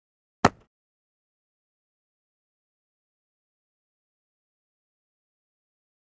Звуки бейсбола
а тут глухой удар